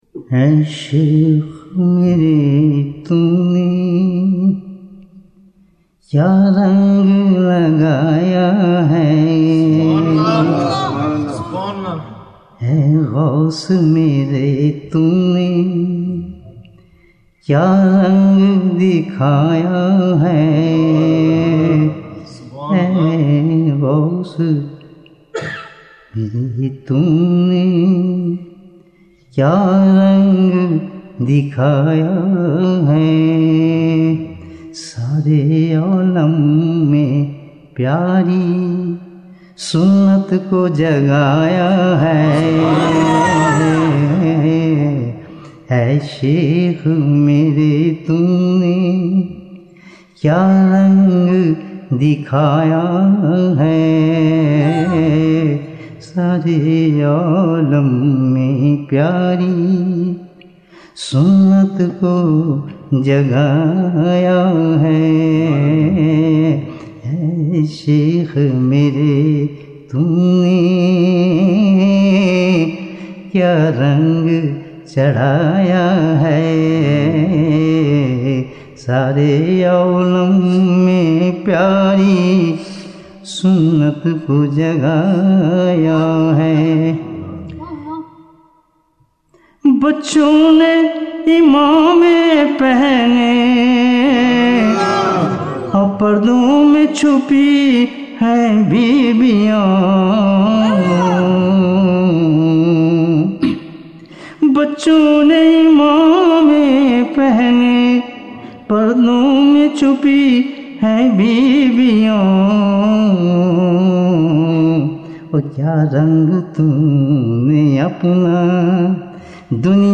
Nazam In Memory of Qutb ul Aqtaab Hadhrat Ali Murtaza (ra) (7 minutes)